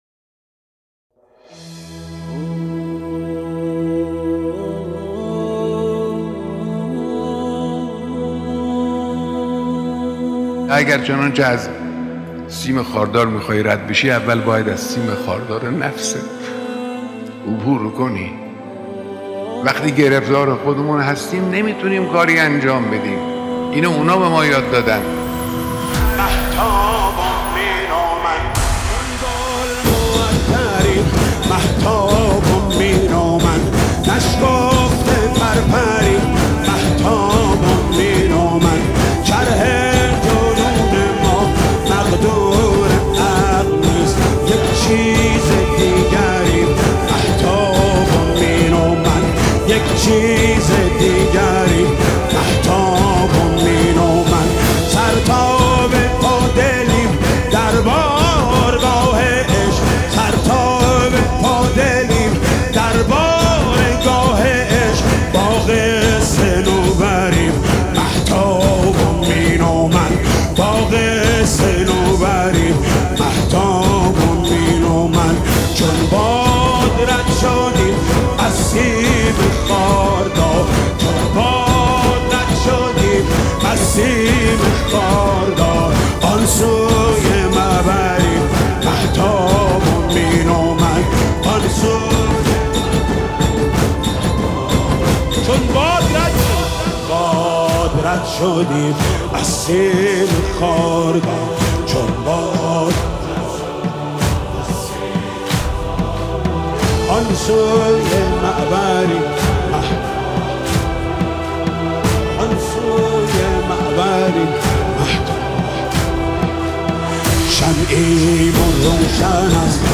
نوحه شب چهارم محرم